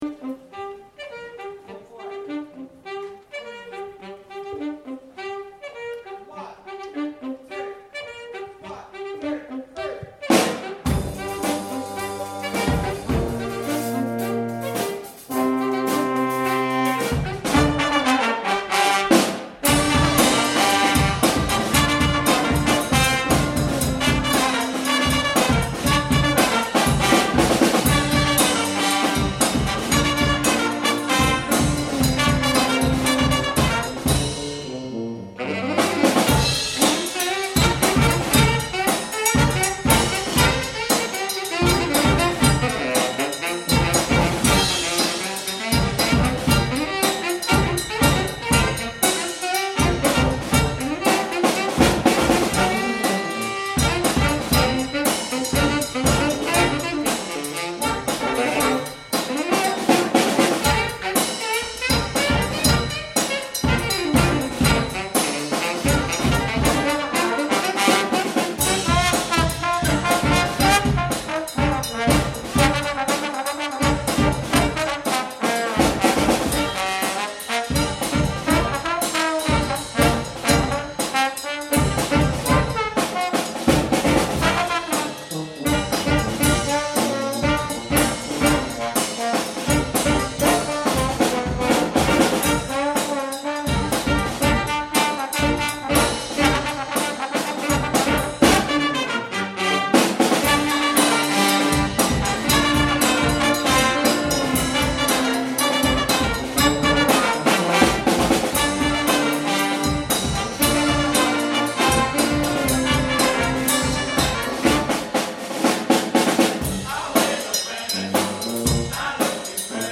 Performed at the Autumn Concert, November 2014 at the Broxbourne Civic Hall.